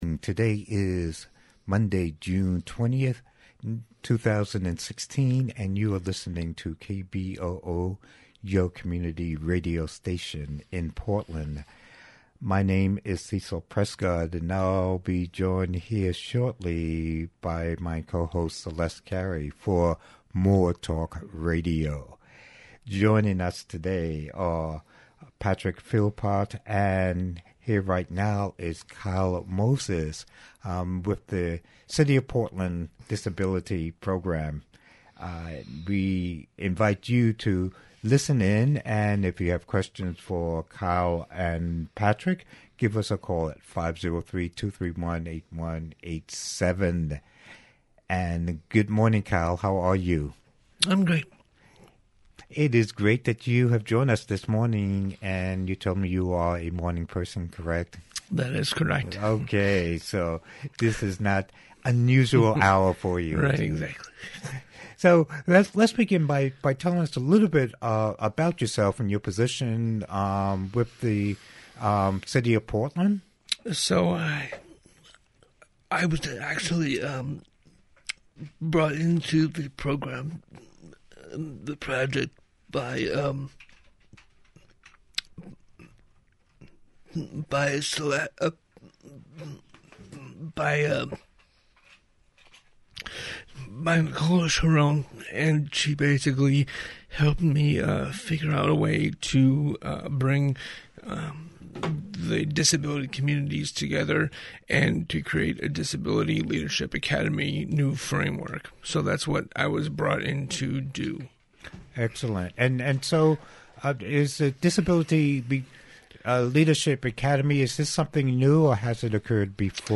Call-in Talk Radio